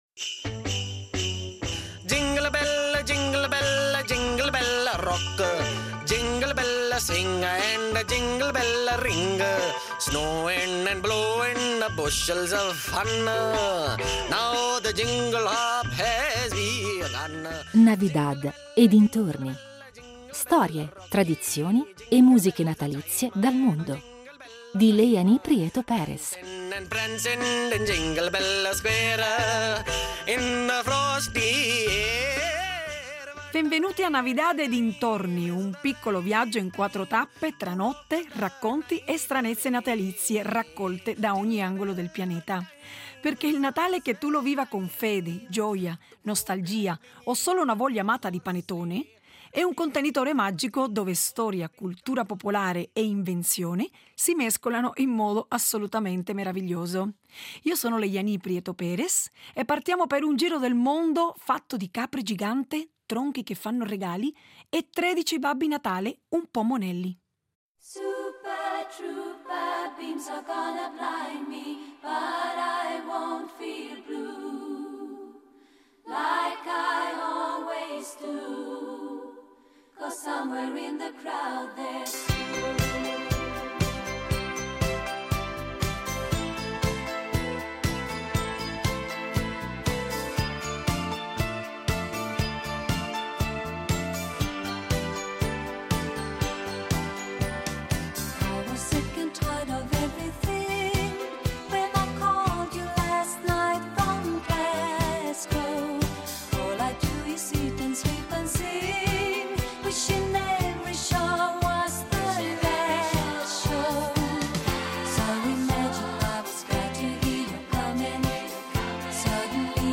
Il tutto attraverso l’ascolto delle loro musiche natalizie, spesso insolite e poco conosciute, alternate ai grandi classici che tutti conosciamo. Oggi partiamo con il nostro affascinante viaggio dalla Svezia, con la sua capra di paglia gigante di Gävle, oggetto di tentativi di incendio annuali.